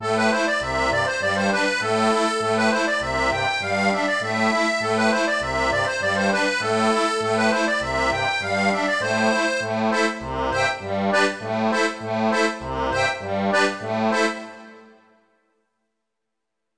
Refrain mélodique et accords sur les couplets
Chanson française